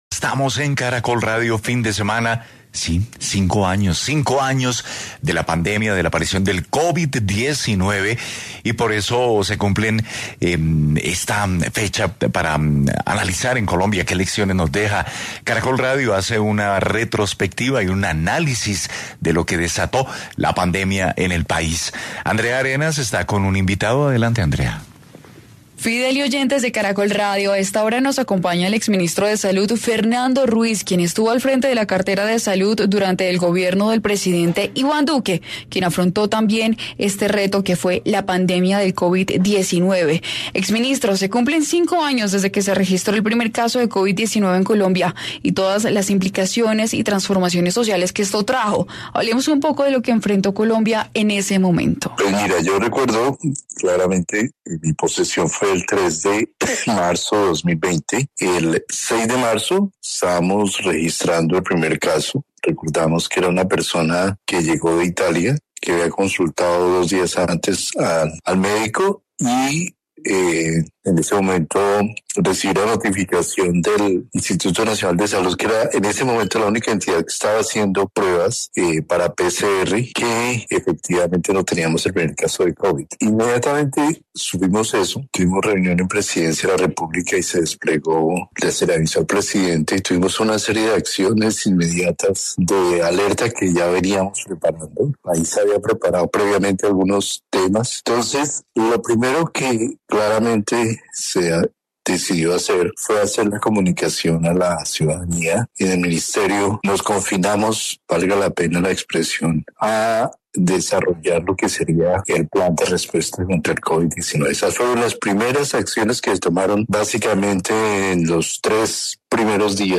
En diálogo con Caracol Radio, el Exministro de Salud, Fernando Ruiz, jefe de esta cartera durante el gobierno del Expresidente Iván Duque, hizo un recuento y a análisis de lo que el país vivió en aquel momento, y los efectos que dejó la pandemia.